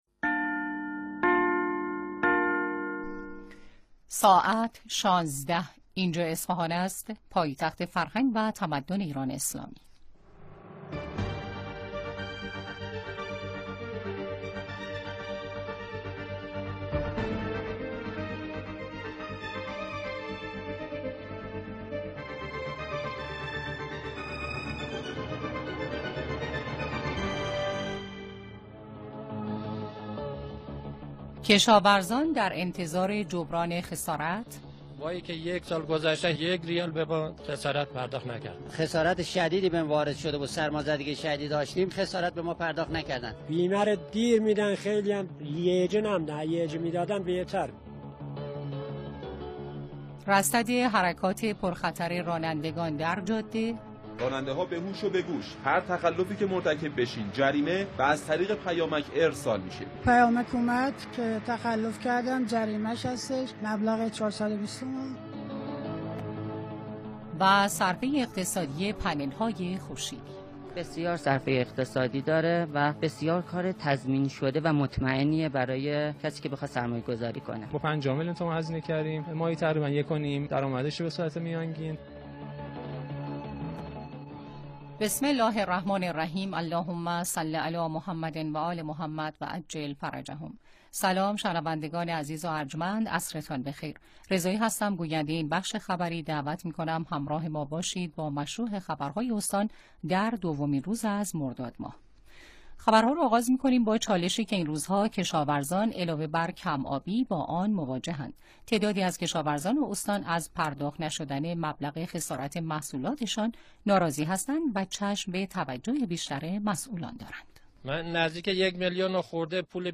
مهمترین رویدادهای خبری استان اصفهان را در بخش خبری 16 رادیو دنبال کنید.